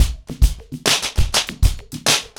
PrintOuts-100BPM.11.wav